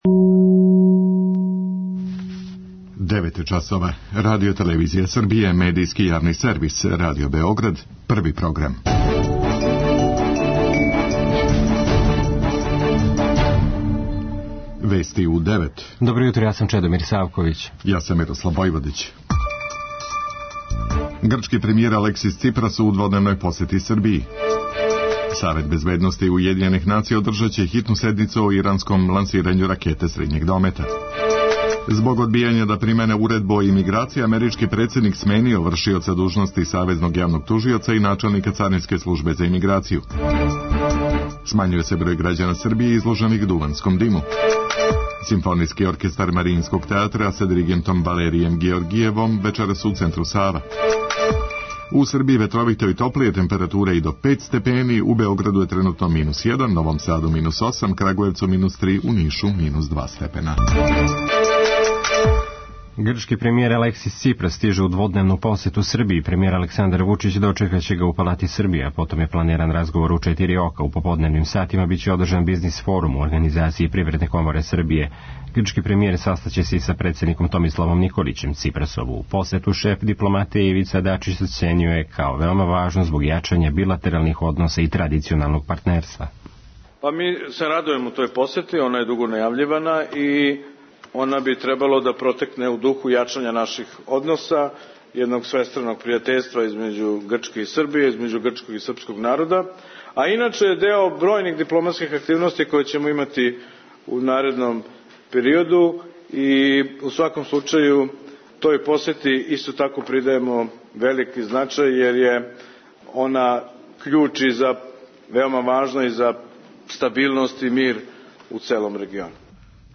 преузми : 3.73 MB Вести у 9 Autor: разни аутори Преглед најважнијиx информација из земље из света.